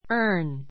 earn A2 ə́ː r n ア ～ン 動詞 （働いて金を） 稼 かせ ぐ earn $10 an hour earn $10 an hour 1時間に10ドル稼ぐ He earns money (by) delivering newspapers.